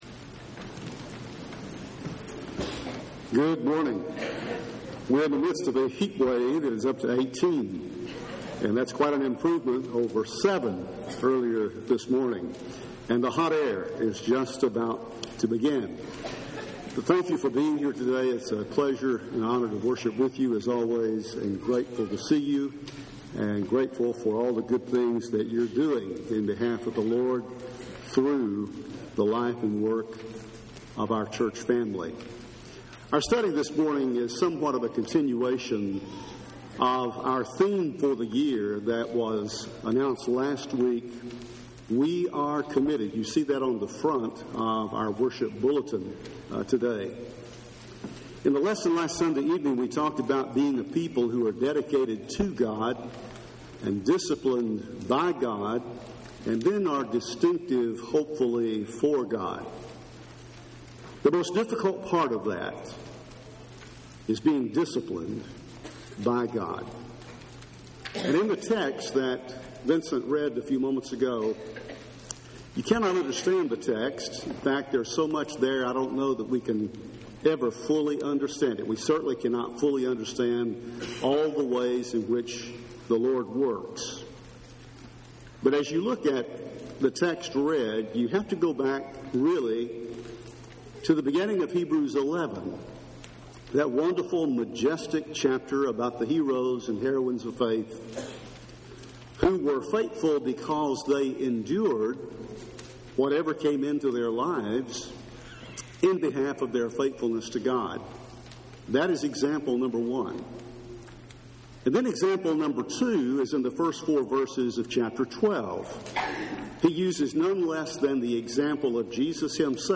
The Lord Disciplines the One He Loves – Henderson, TN Church of Christ